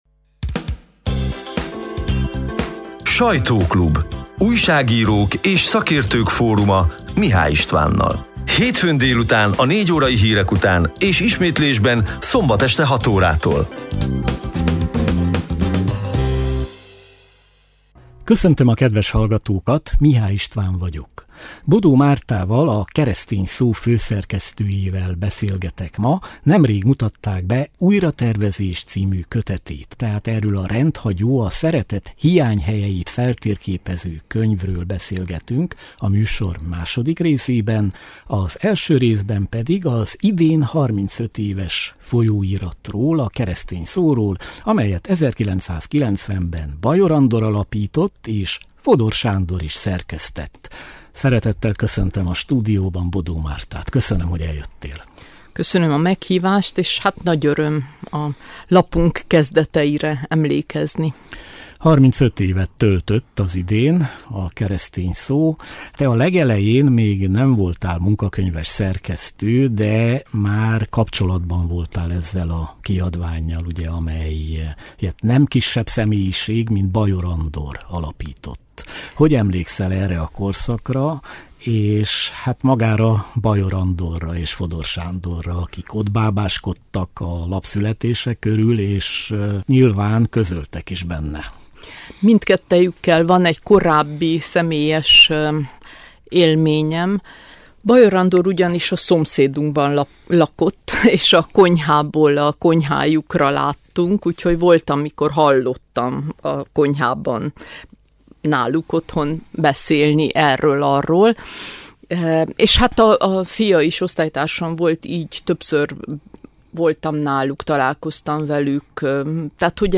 A lejátszóra kattintva a november 24-i, hétfő délutáni élő műsor szerkesztett, kissé rövidített változatát hallgathatják meg.